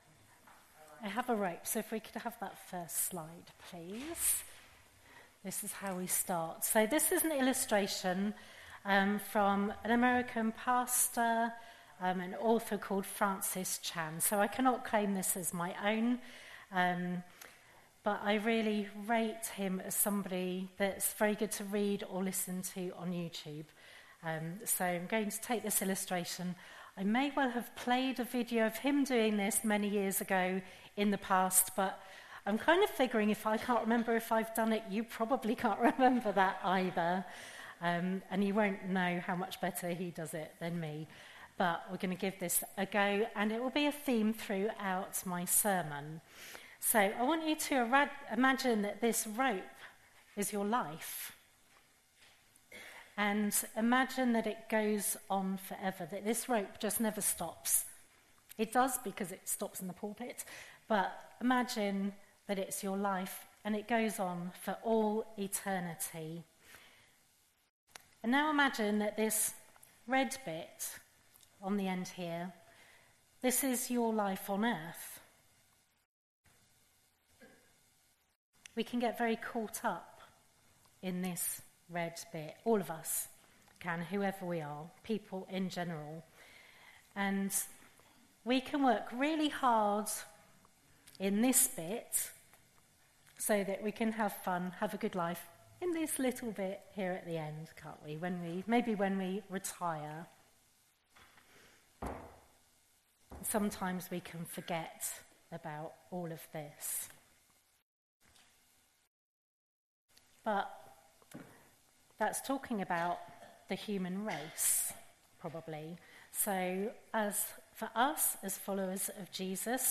Passage: Luke 6:27-38, Psalm 37:1-11,39-40 Service Type: Sunday Morning
02-23-sermon.mp3